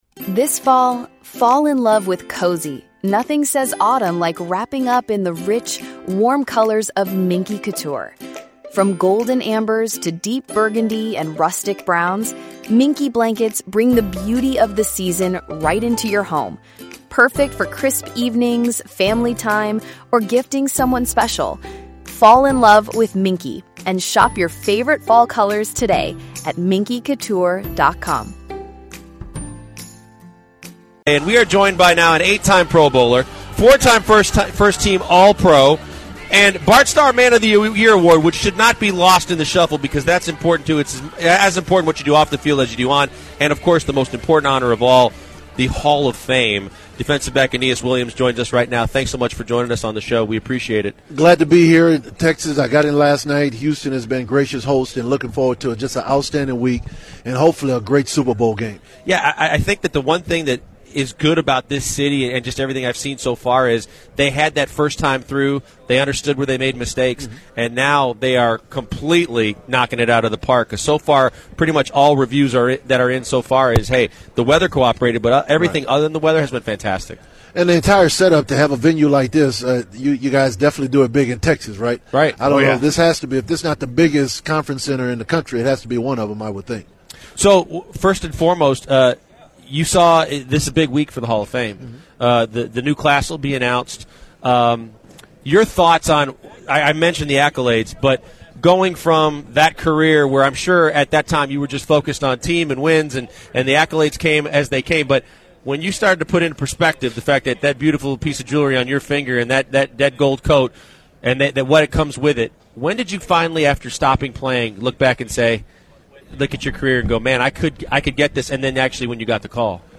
The guys speak with NFL Hall of Famer Aeneas Williams, who is representing Kay Jewelers' partnership with St. Jude and how you can donate to help St. Jude researches find cures.